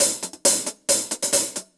UHH_AcoustiHatB_135-03.wav